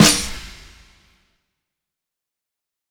af_snr.wav